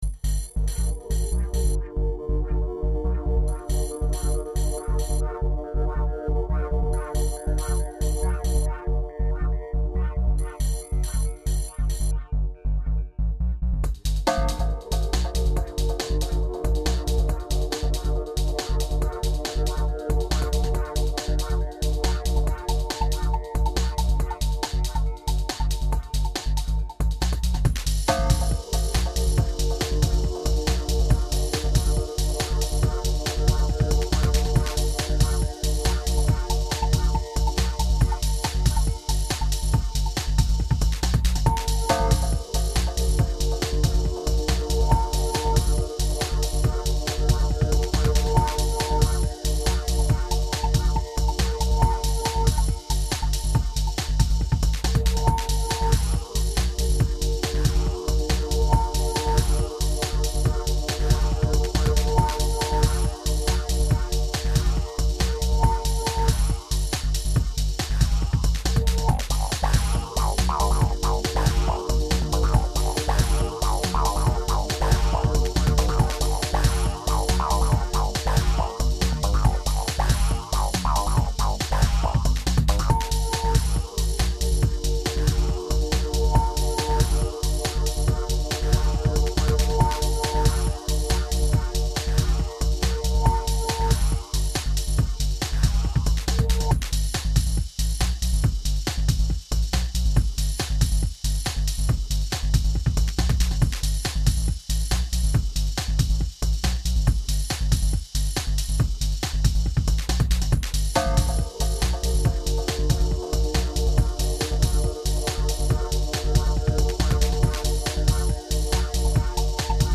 Lately I've been playing with some music software that allows you to make loop based music.
This is a nice bass line and something semi-melodic. Something nice to drive fast to.